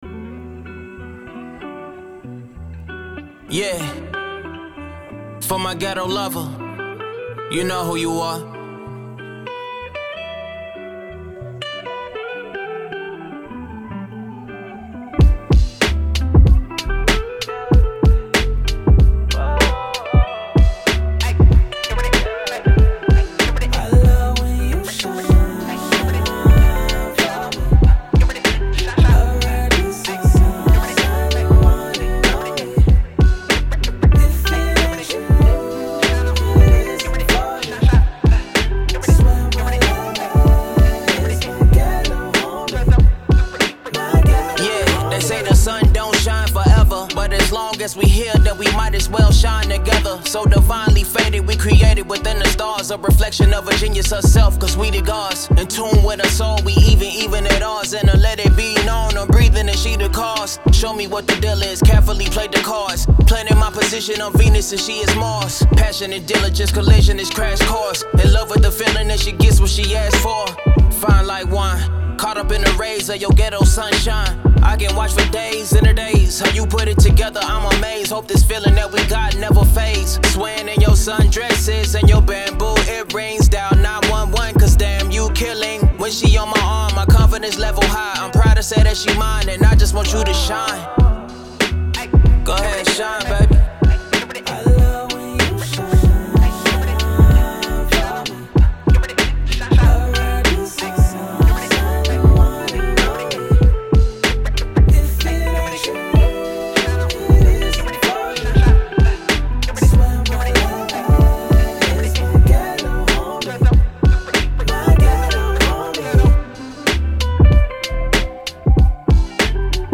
Hip Hop, Rap
E Minor